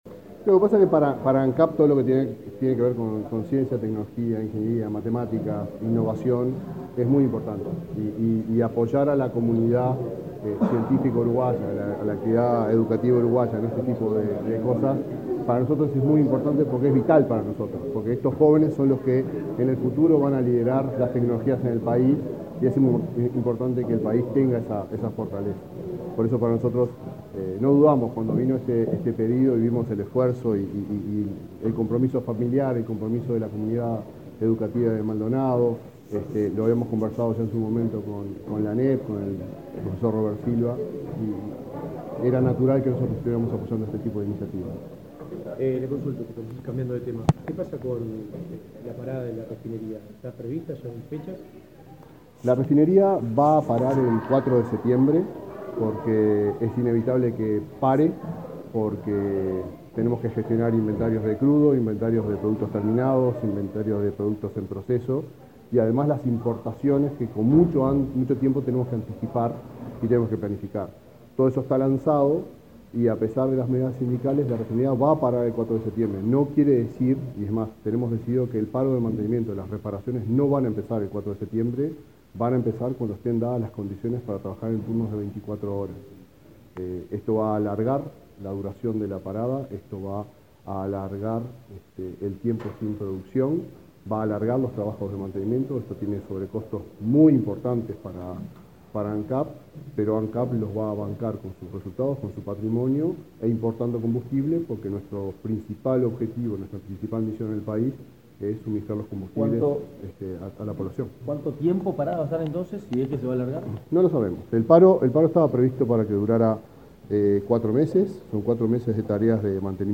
Declaraciones del presidente de Ancap a la prensa
El presidente de Ancap, Alejandro Stipanicic, participó en el acto y luego dialogó con la prensa.